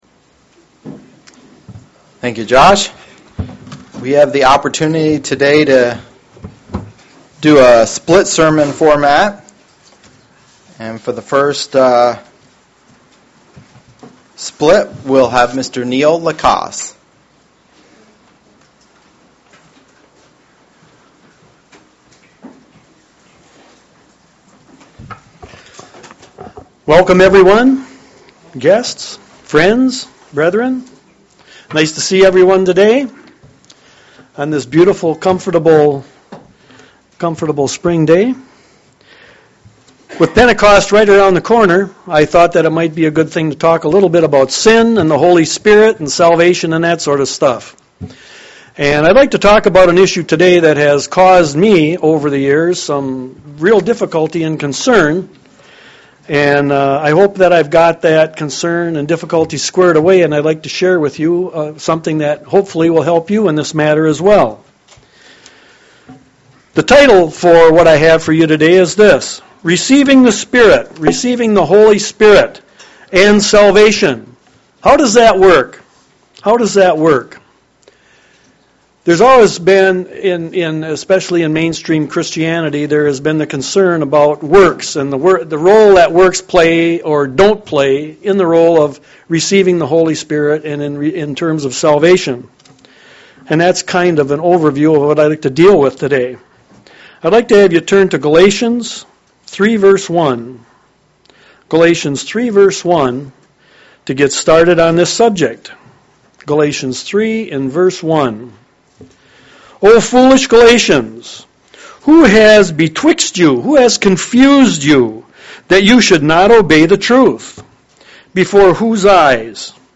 UCG Sermon Studying the bible?
Given in Lansing, MI